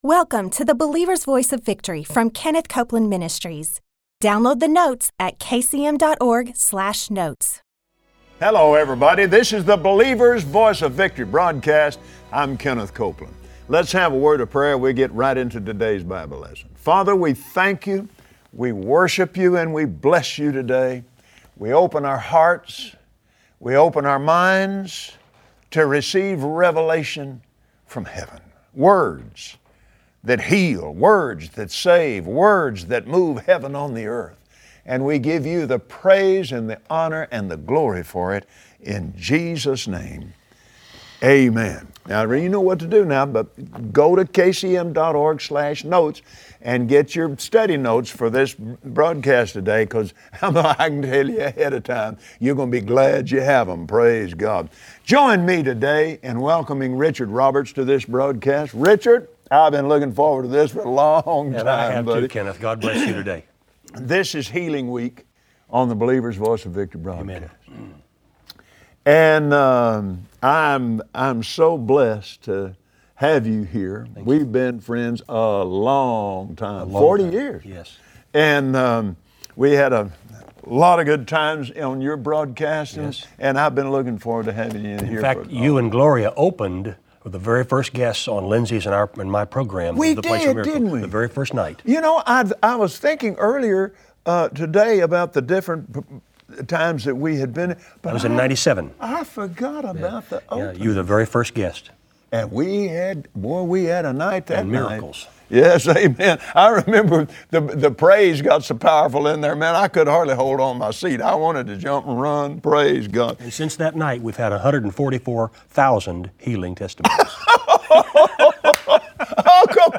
Believers Voice of Victory Audio Broadcast for Monday 09/14/2015 Today, Kenneth Copeland welcomes Richard Roberts to the Believer’s Voice of Victory.